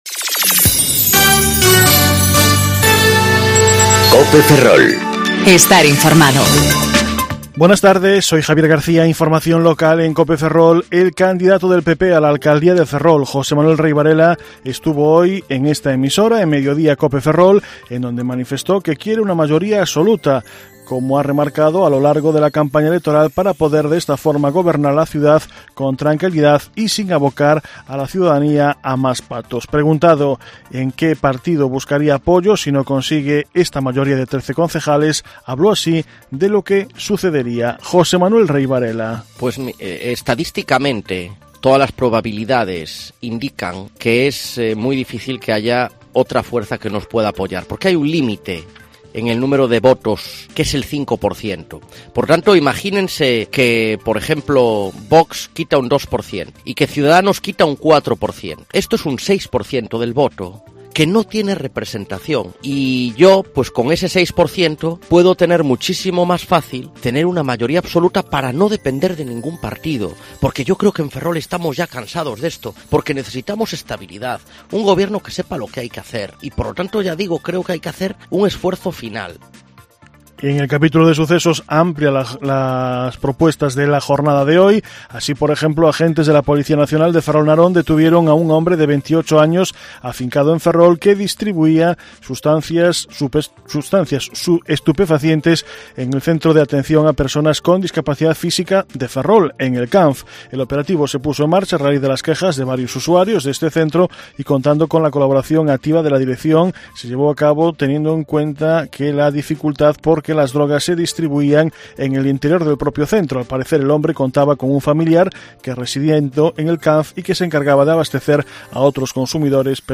Informativo Mediodía Cope Ferrol 24/05/2019 (De 14.20 a 14.30 horas)